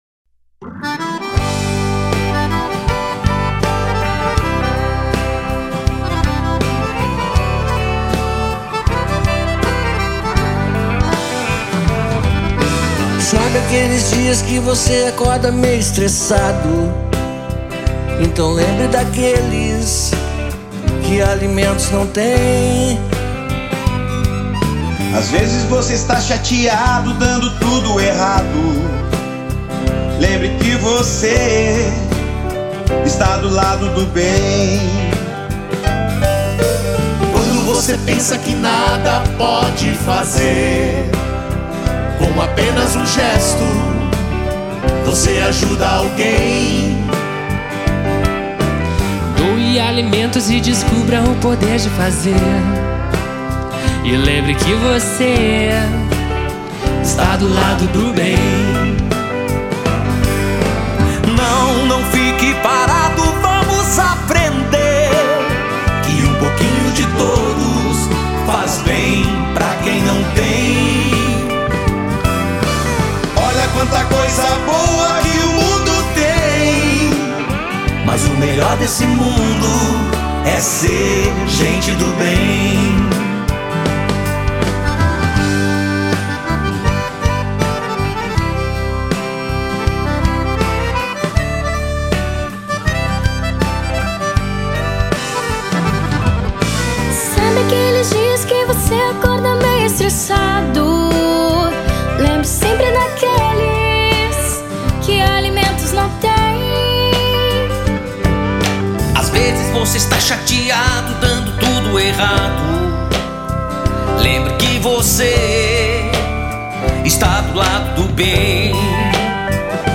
acordeon
baixo
bateria
guitarra havaiana